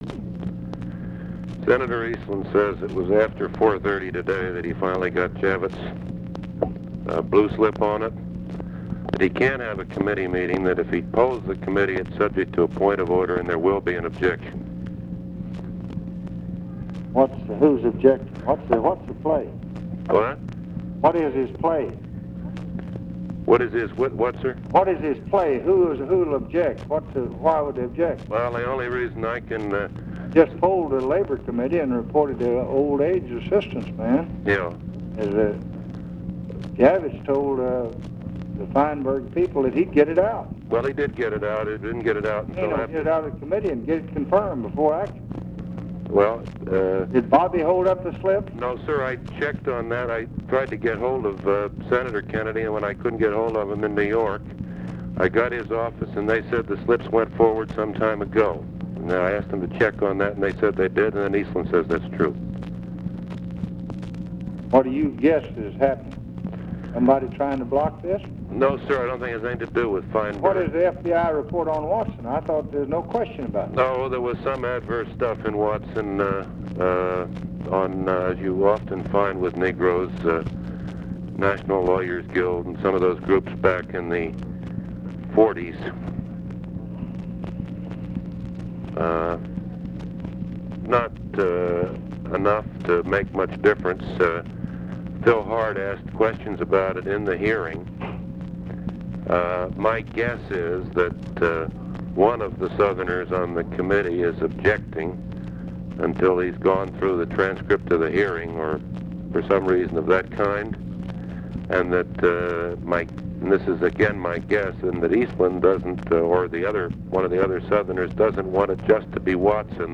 Conversation with NICHOLAS KATZENBACH, October 22, 1965
Secret White House Tapes